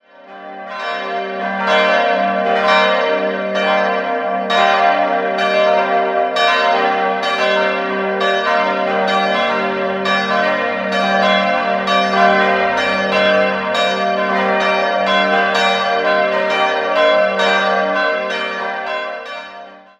Dabei verwendetet man die Inneneinrichtung der bisherigen Kirche wieder, darunter die drei sehenswerten Rokokoaltäre. 4-stimmiges ausgefülltes G-Moll-Geläute: g'-b'-c''-d'' Die Glocken wurden im Jahr 1960 von der Gießerei Friedrich Wilhelm Schilling in Heidelberg gegossen.